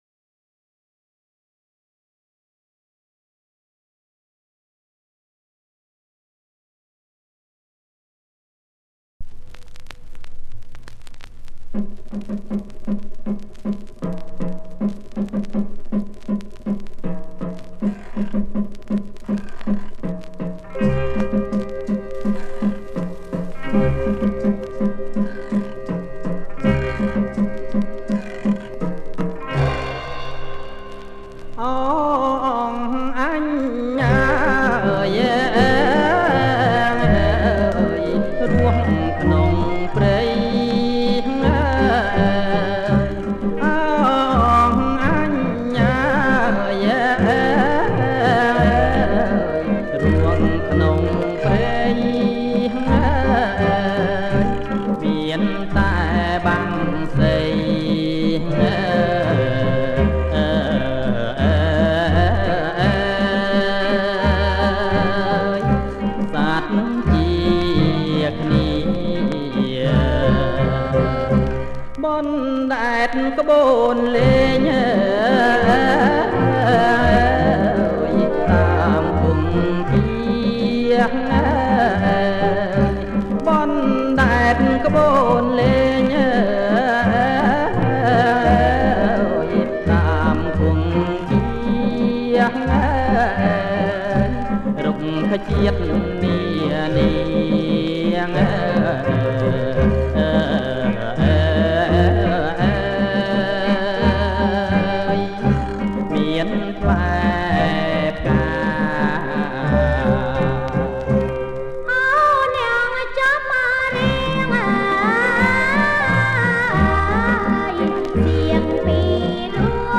• ប្រគំជាចង្វាក់ Bolero Kbach